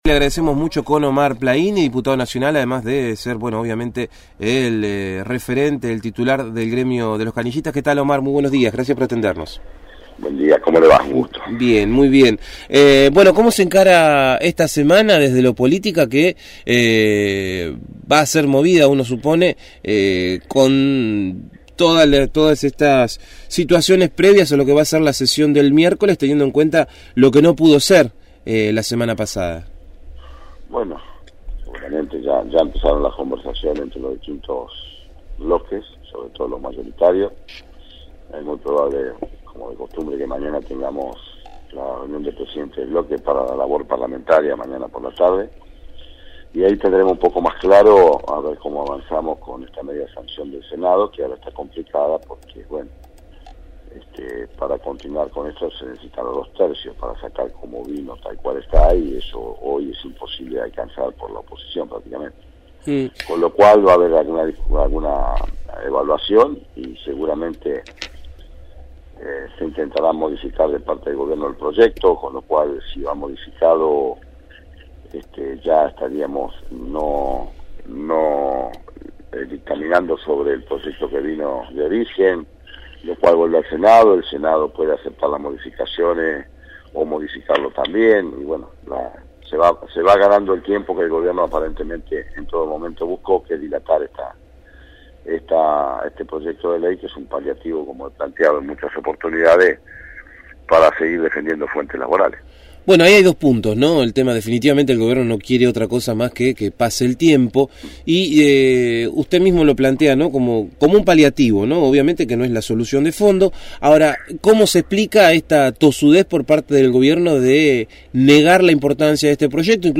Omar Plaini, diputado nacional del bloque Cultura, Educación y Trabajo y jefe del Sindicato de Canillitas, dialogó con el equipo de «El Hormiguero» sobre el debate del proyecto de ley «anti-despidos» que, tras la falta de quórum del jueves pasado, se debatirá en Diputados el próximo miércoles.